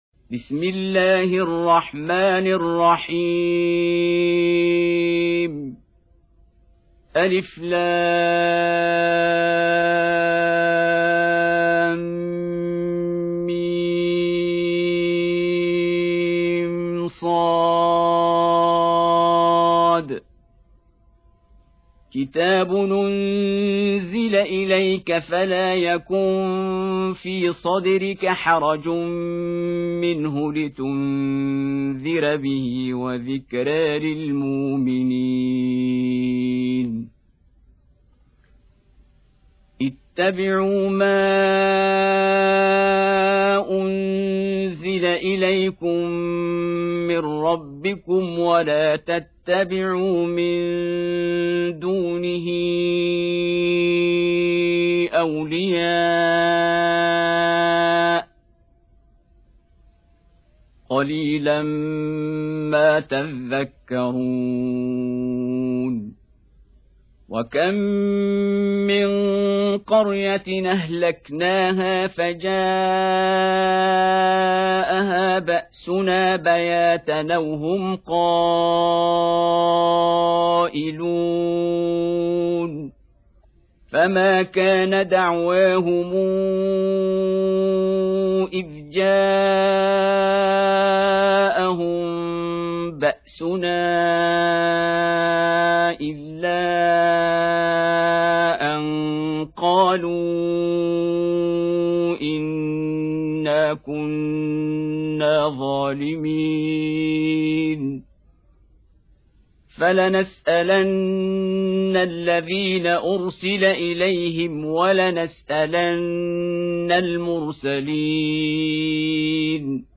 Surah Repeating تكرار السورة Download Surah حمّل السورة Reciting Murattalah Audio for 7. Surah Al-A'r�f سورة الأعراف N.B *Surah Includes Al-Basmalah Reciters Sequents تتابع التلاوات Reciters Repeats تكرار التلاوات